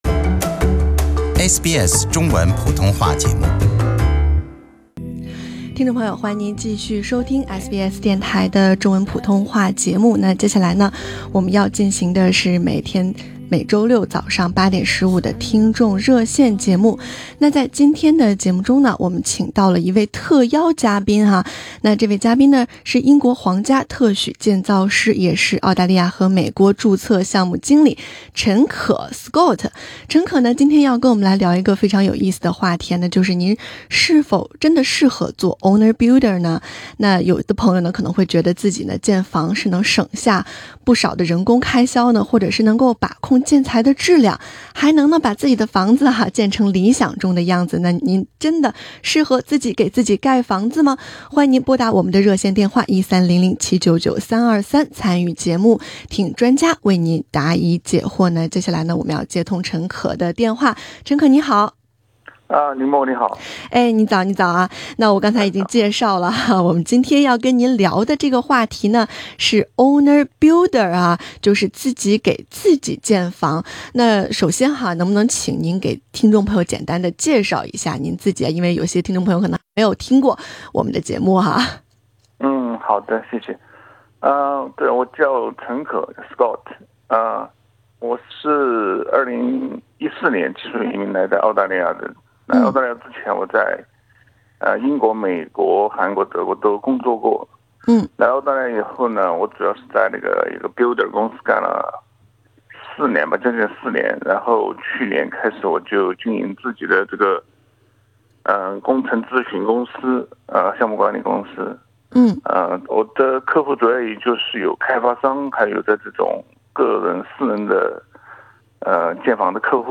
听众热线特别节目：您适合做Owner Builder吗？